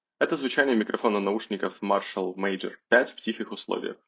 Микрофон наушников Marshall Major 5 отличного качества, на 9 из 10.
В тихих условиях: